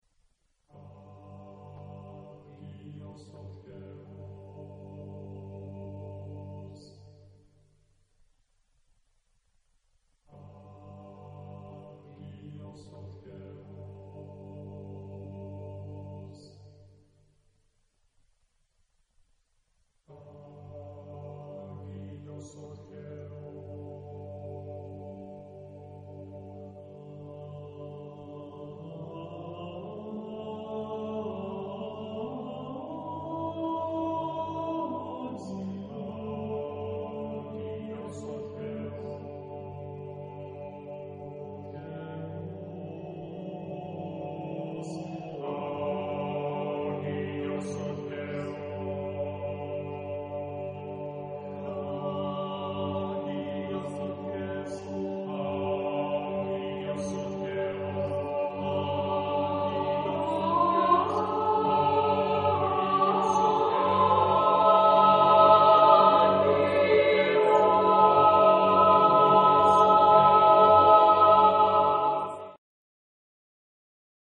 Epoque: 20th century  (1990-2000)
Genre-Style-Form: Sacred ; Prayer ; Choir
Mood of the piece: lament ; prayerful
Type of Choir: SSAATTBB  (8 mixed voices )
Tonality: C major